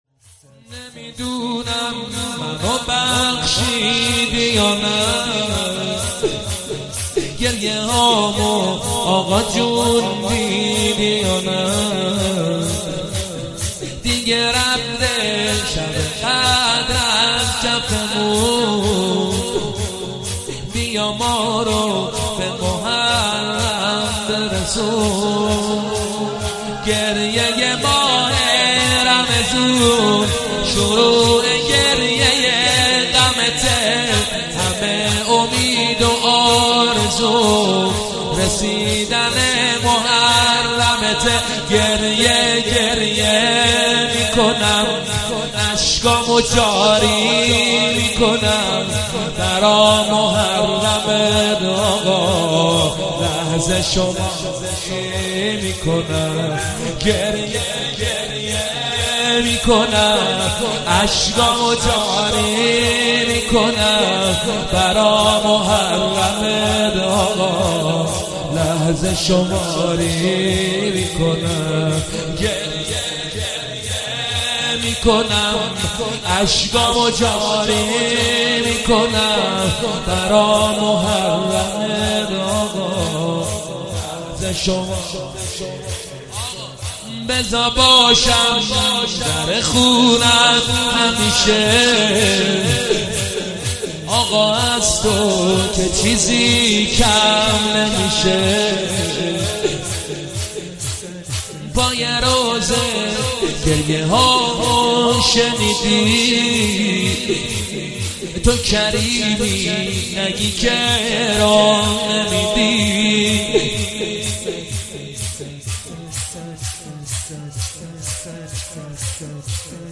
مداح